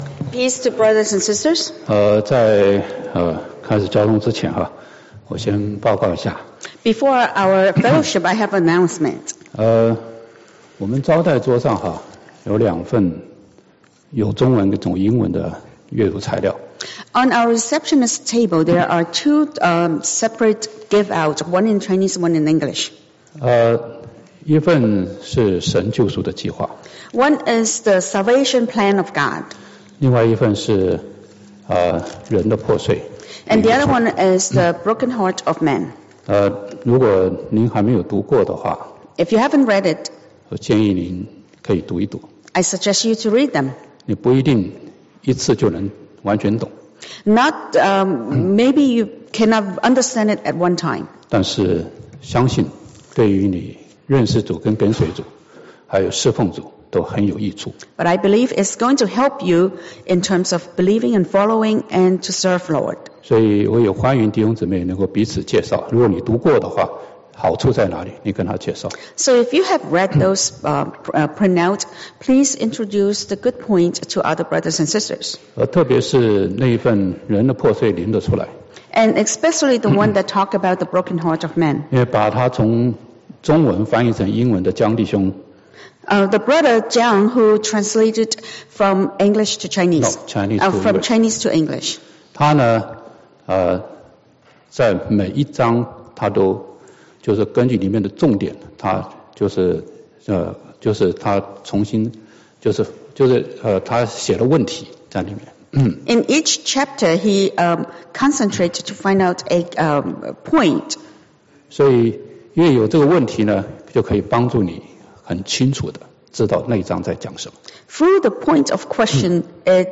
弟兄分享约翰福音14-17章的祷告